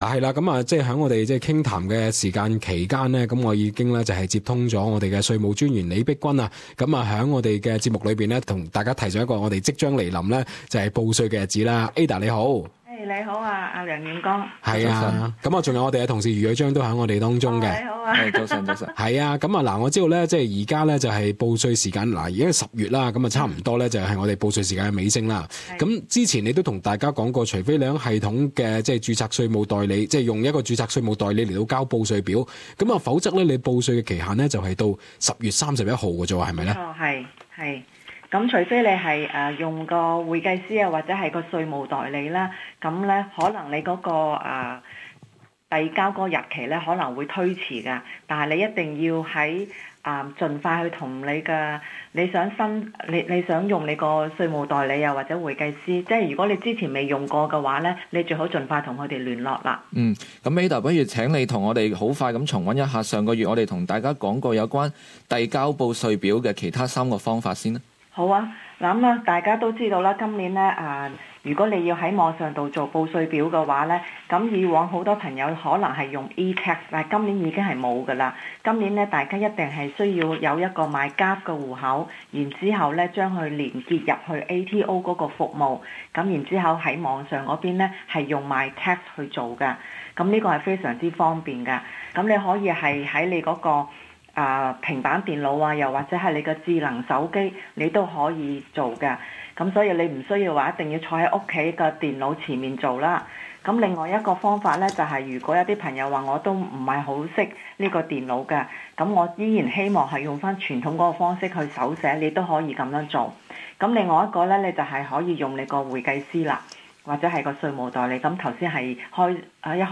以下社区资讯，是由澳洲税务局带给你。